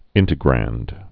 (ĭntĭ-grănd)